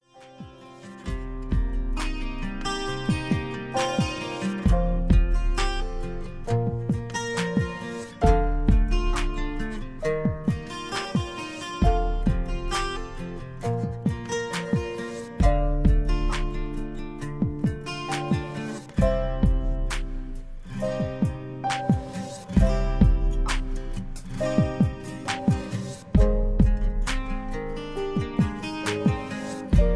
karaoke, mp3 backing tracks
country music